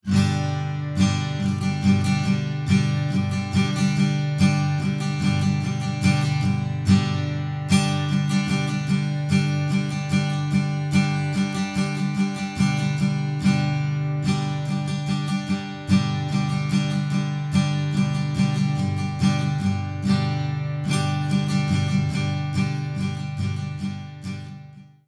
ascoltiamo, un tipico strumming fatto sull'accordo di G, la cui diteggiatura è indicata nel grafico sotto la partitura. La ritmica, come vedete, è molto semplice, ed è suonata con una chitarra acustica (Bozo USA 1977, registrata in diretta tramite un semplicissimo shure SM57), utilizzando un plettro JD 0.70 di medio spessore.